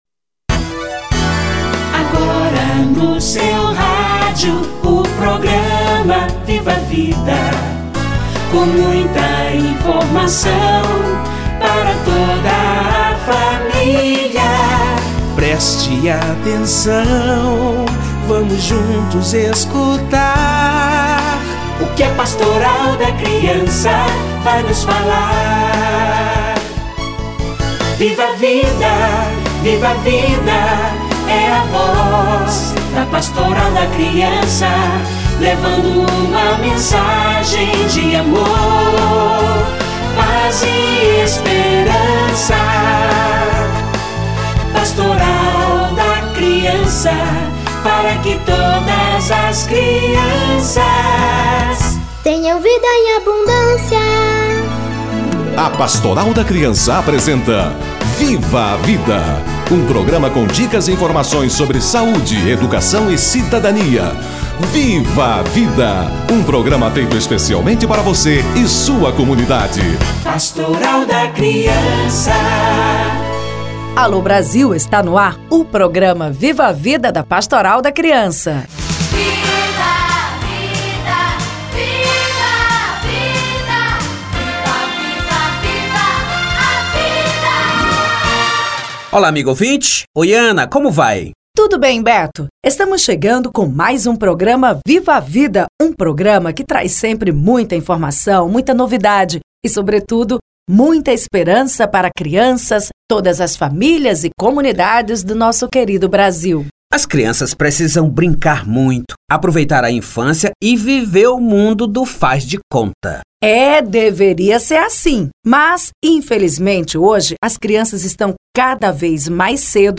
Adultização da criança - Entrevista